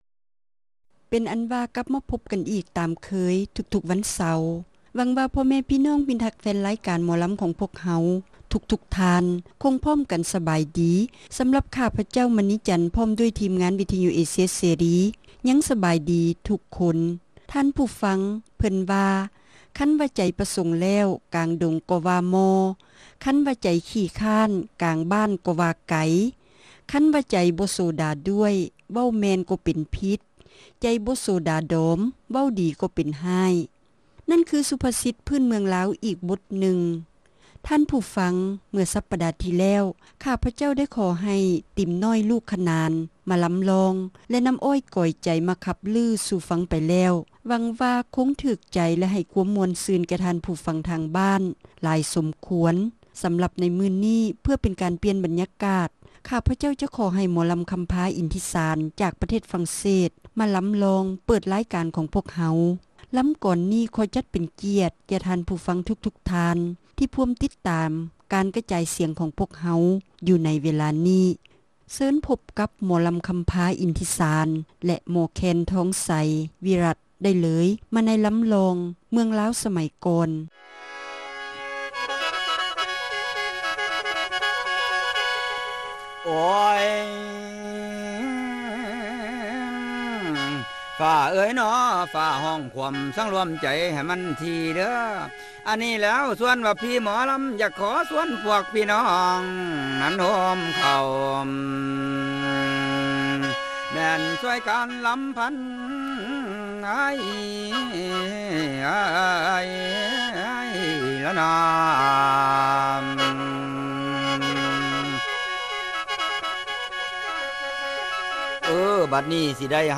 ຣາຍການໜໍລຳ ປະຈຳສັປະດາ ວັນທີ 19 ເດືອນ ຕຸລາ ປີ 2007